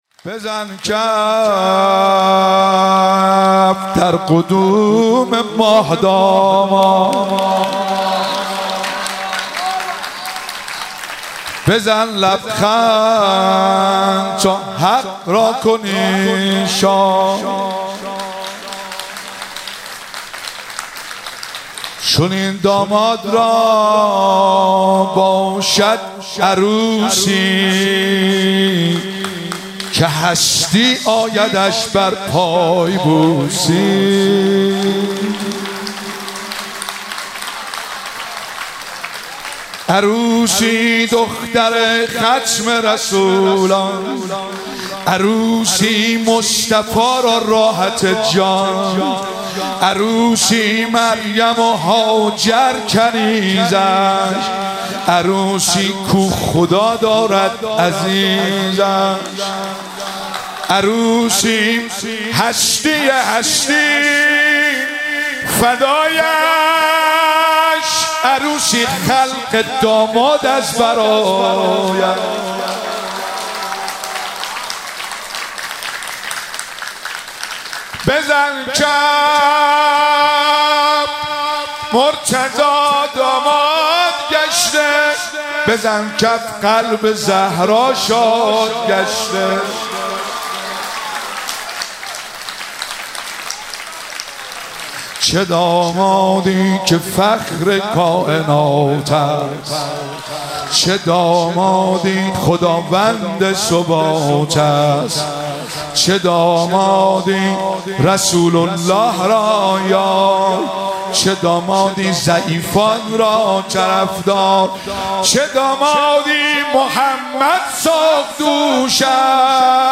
مدح
مراسم جشن سالگرد ازدواج حضرت امیرالمومنین علی علیه السلام و حضرت فاطمه زهرا سلام الله علیها- خرداد 1402